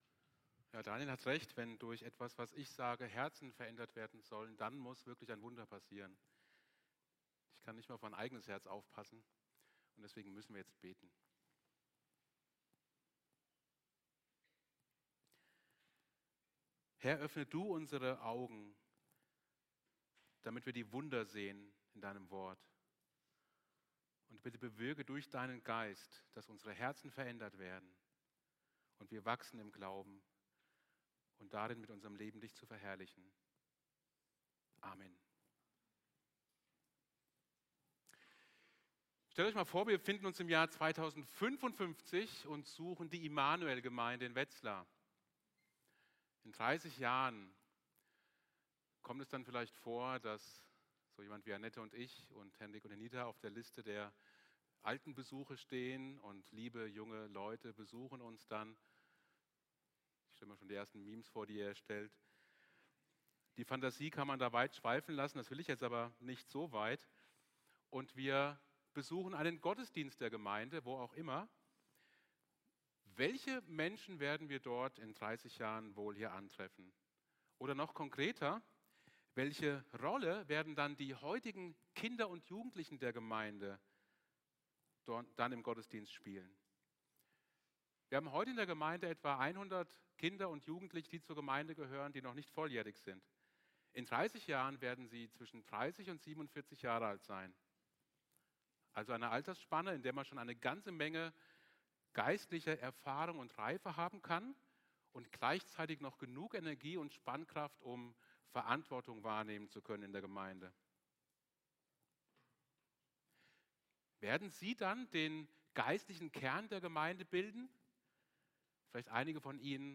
Serie: Einzelne Predigten